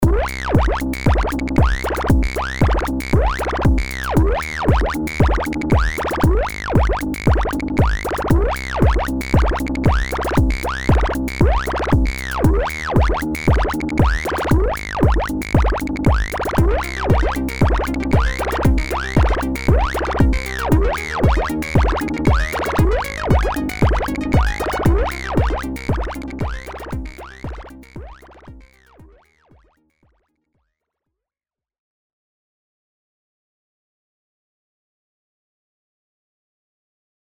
参考例としてStepperとArpeggiatorを使用したデモトラックを作成してみました。
シンセベースのトラックはStepper、高域のシーケンストラックはArpeggiatorのステップシーケンサーを使用しています。この高域のシーケンスフレーズのパートにはLFO1を使用してオシレーター1の定位を周期的に変化させることによってオートパン効果を付加しています。
Step_Arp_demo.mp3